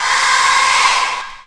Waka- AHHH.wav